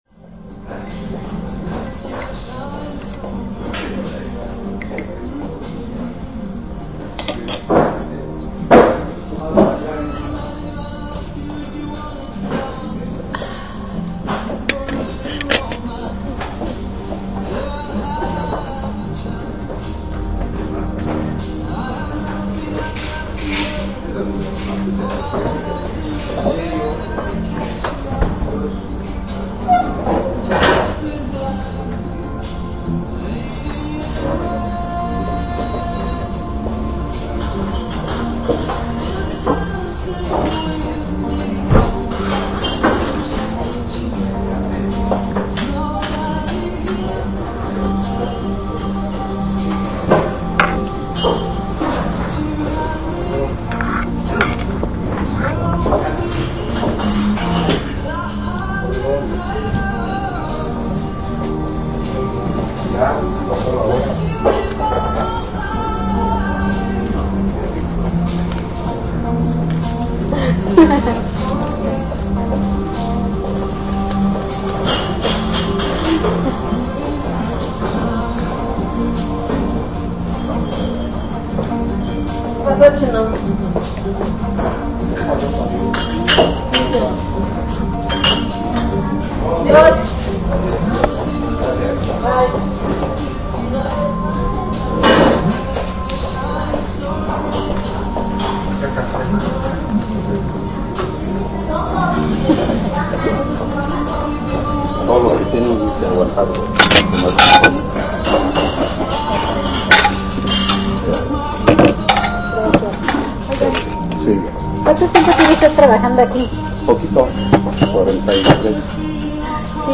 Restaurante Trevi habita en la esquina de la Alameda Central en la Ciudad de Mexico, donde la nostalgia nos recibe de golpe.